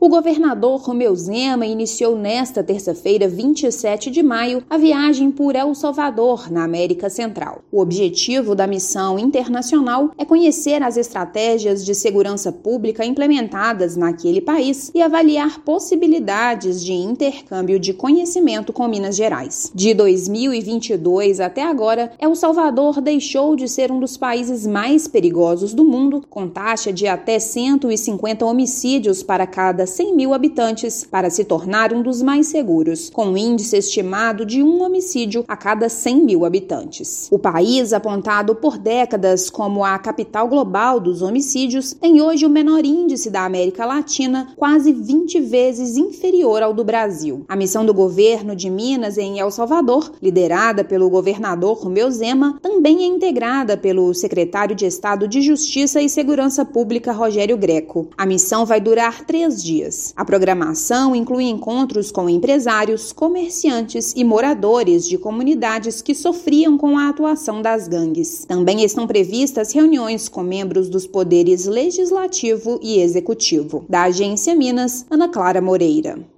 Missão durará três dias e se soma a uma série de investimentos que a atual administração tem direcionado às Forças de Segurança. Ouça matéria de rádio.